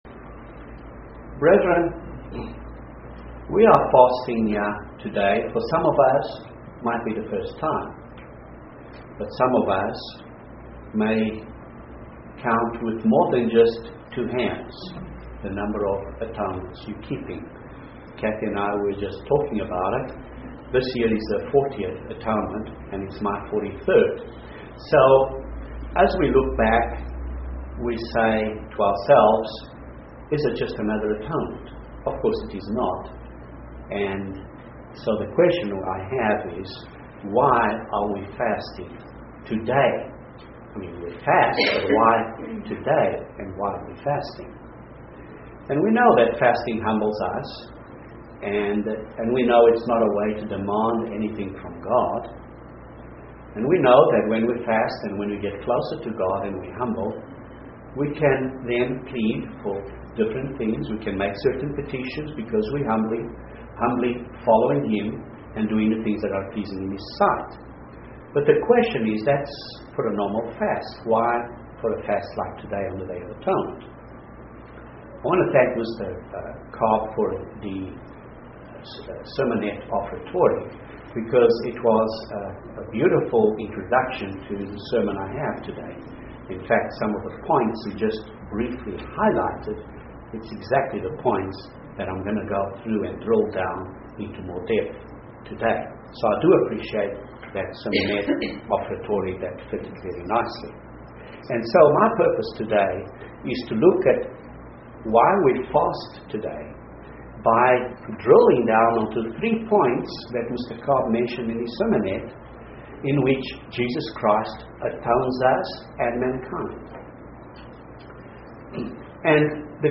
This sermon looks at 3 ways that Jesus atones us. The end goal is that we may be perfect in all that is good, to fulfill His wish in us, doing what is pleasing to God, through Jesus Christ, to whom be the glory forever!